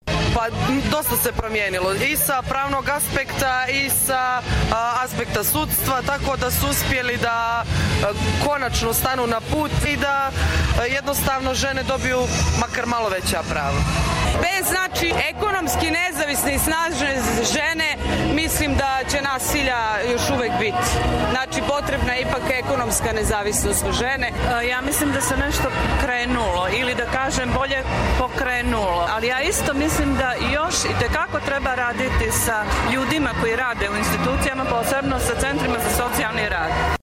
Na podgoričkom Trgu Republike održan je ples protiv nasilja u organizaciji Sigurne ženske kuće, čime se i Crna Gora i ove godine priključila međunarodnoj borbi protiv nasilja nad ženama i djevojkama.
Učesnice smo pitali o stanju u oblasti prava žena i nasilja nad njima, odnosno da li se nešto promjenilo:
Žene o nasilju